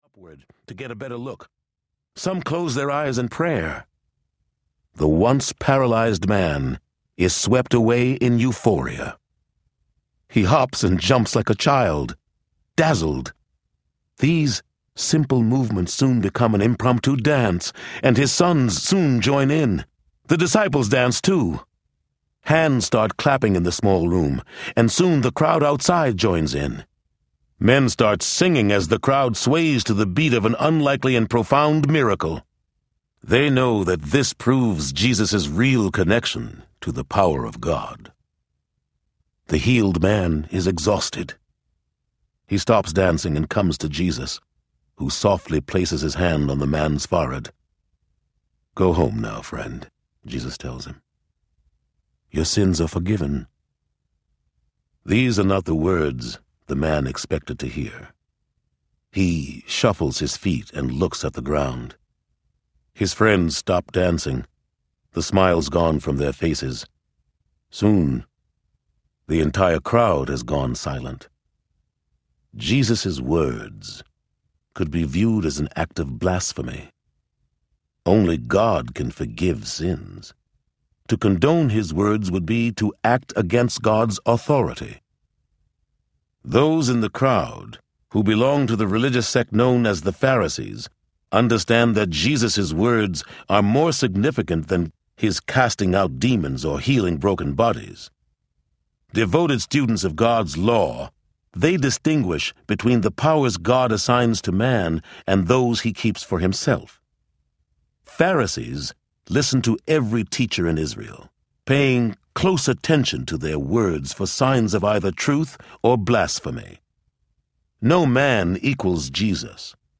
Son of God Audiobook
Narrator